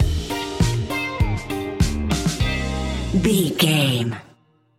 Ionian/Major
house
synths
techno
trance
instrumentals